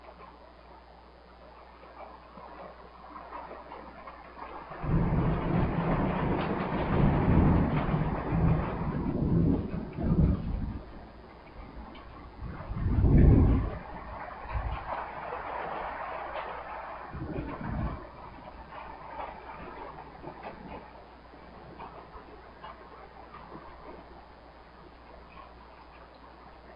自然的声音 " 雷霆室内2
描述：记录从室内，雷鸣般的雷鸣。雷电雷雨天气雷雨滚滚雷声隆隆声
Tag: 闪电 天气 滚动雷 雷暴 雷暴 隆隆